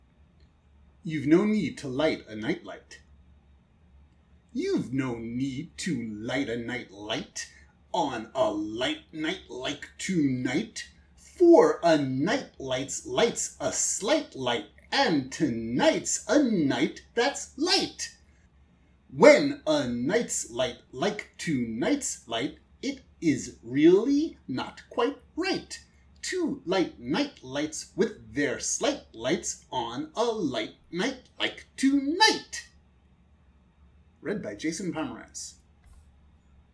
This poem can be recited as a tongue twister...
nightlight-tongue-twister_jp.mp3